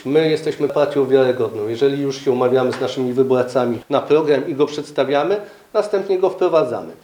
Parlamentarzysta zapewnia, że w budżecie znajdą się pieniądze na te projekty.